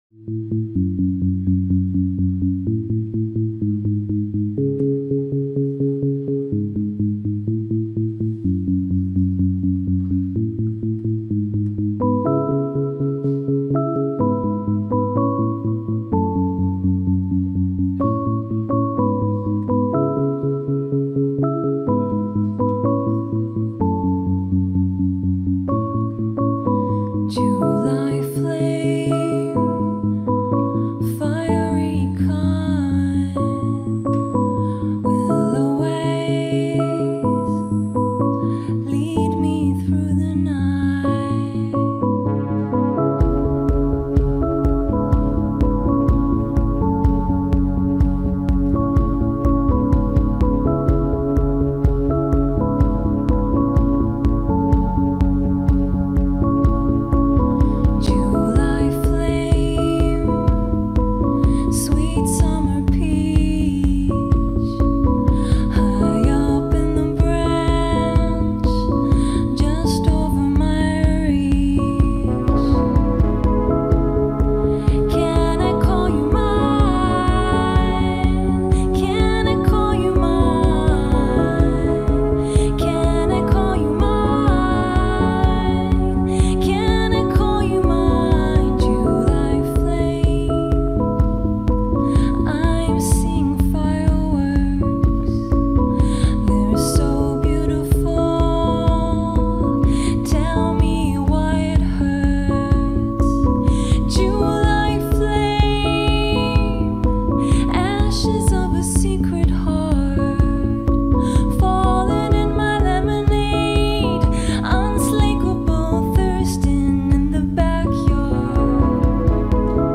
in concert at Folies Bergère
she paints a luxuriant soundscape